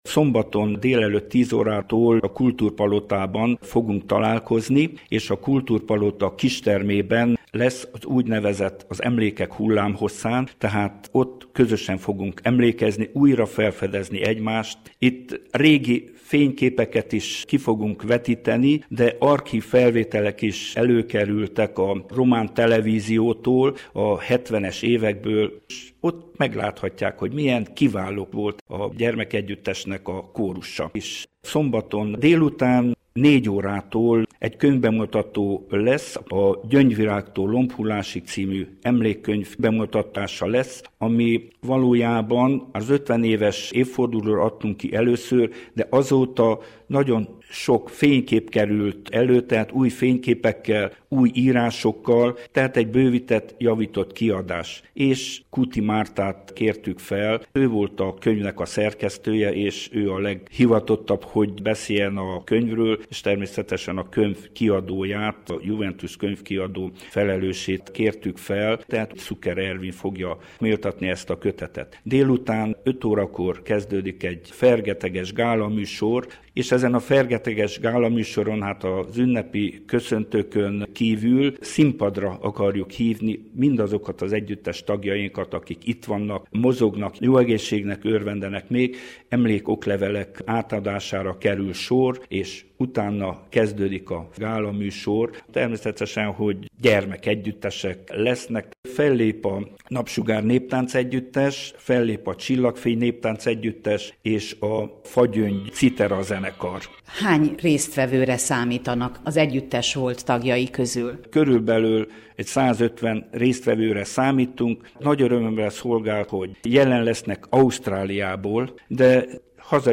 interjújában